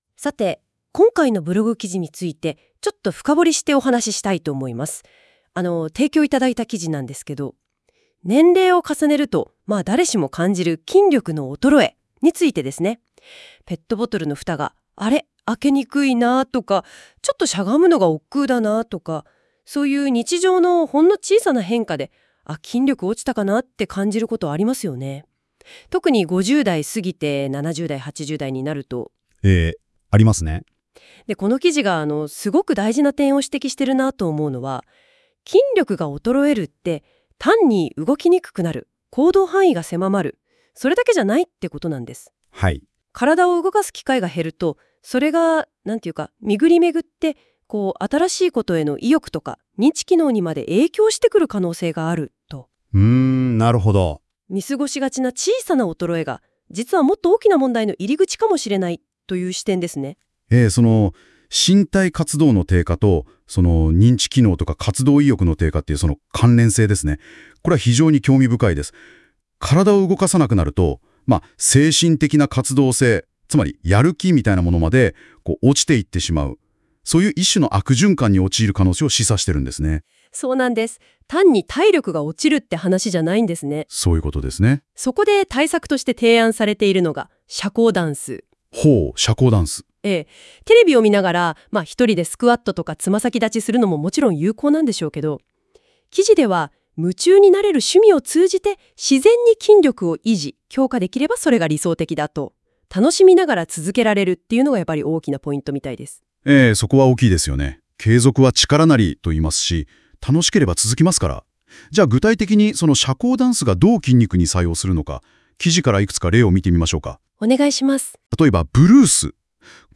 このブログについて、AIで生成した会話音声（約5分）をお楽しみいただけます。